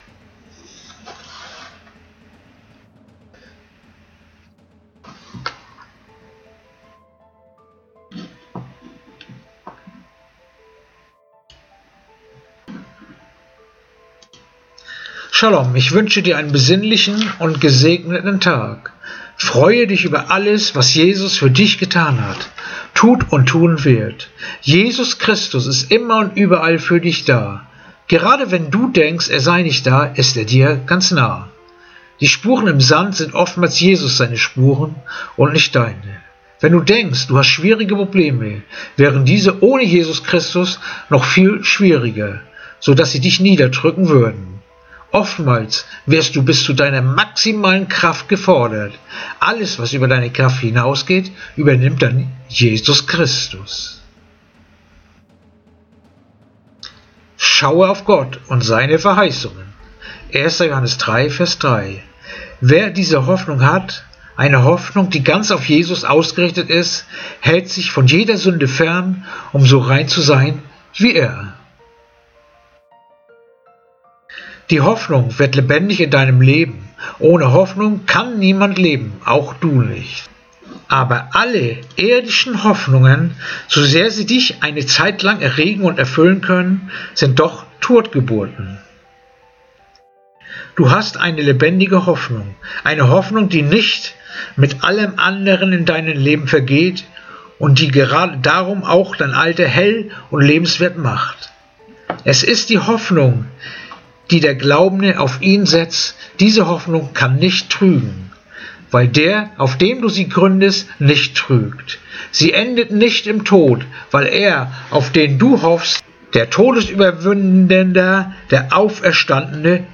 Andacht-vom-24.-März-1.-Johannes-3-3